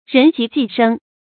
人極計生 注音： ㄖㄣˊ ㄐㄧˊ ㄐㄧˋ ㄕㄥ 讀音讀法： 意思解釋： 謂人到絕境就會產生計謀。